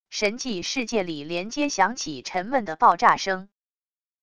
神迹世界里连接响起沉闷的爆炸声wav音频